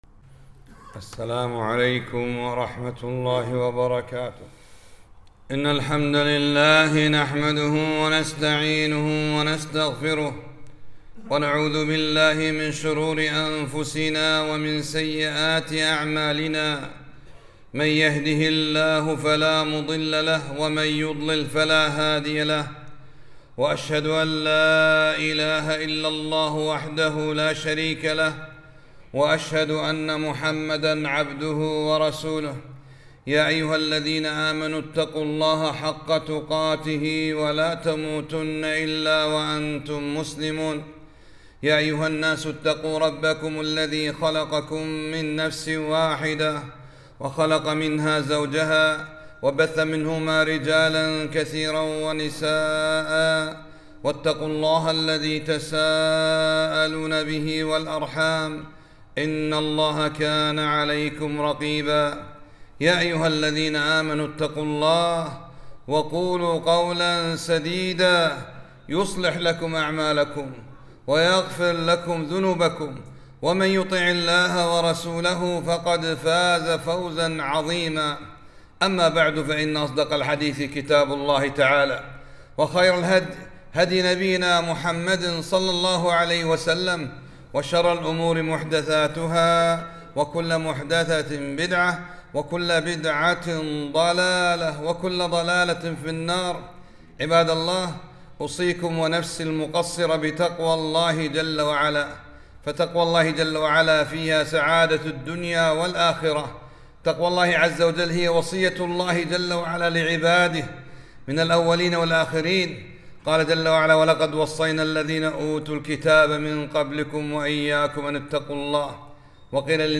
خطبة -
خطبة - " رضيت بالله ربا "